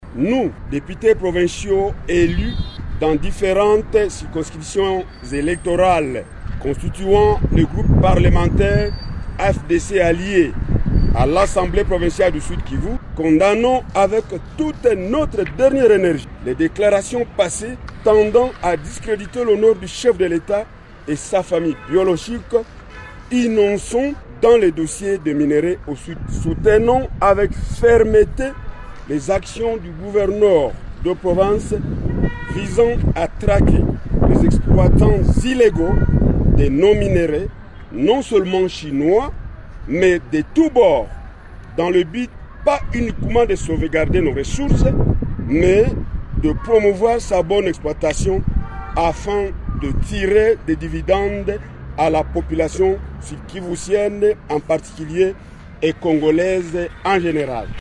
Suivez un extrait de leur déclaration lu ici par le député MOKE BIHINDA Jérôme président des députés du groupe parlementaire AFDC-A à l’assemblée provinciale du Sud-Kivu 00000